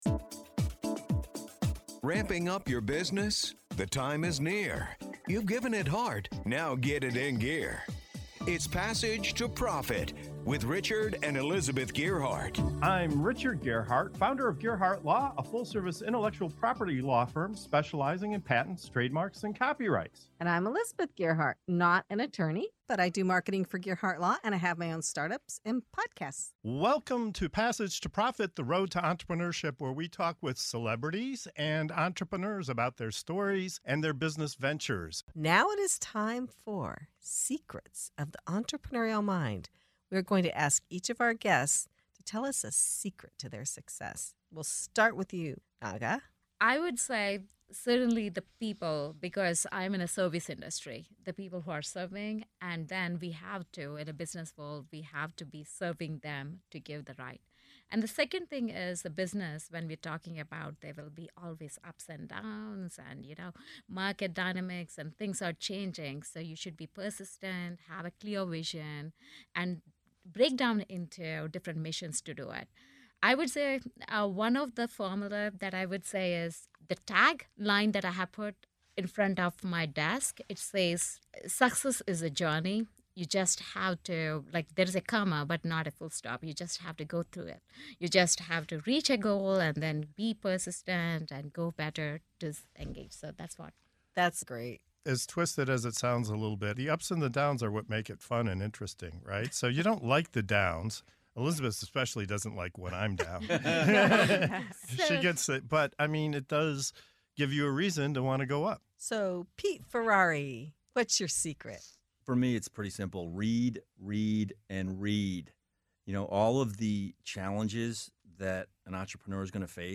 In this segment of "Secrets of the Entrepreneurial Mind" on Passage to Profit Show, we uncover the key habits, mindsets, and strategies that fuel success. Our guests share their personal formulas—from persistence and clarity of vision to the power of reading and taking action.